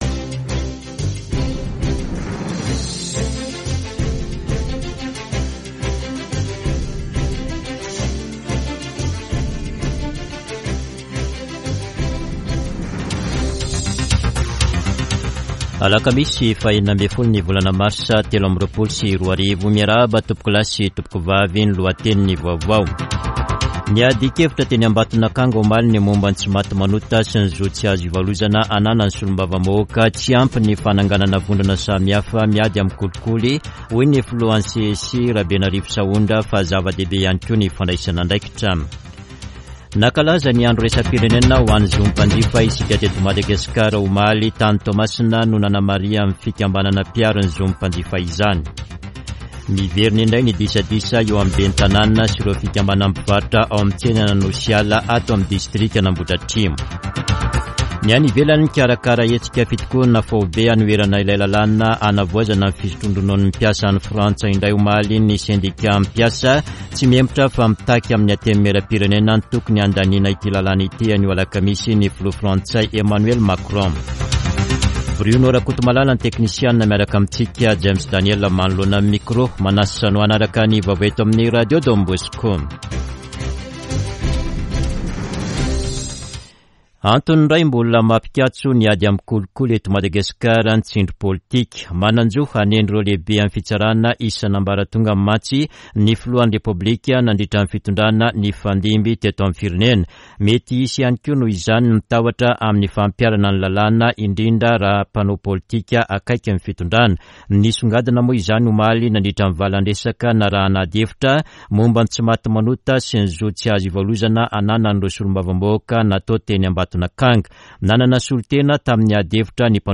[Vaovao maraina] Alakamisy 16 marsa 2023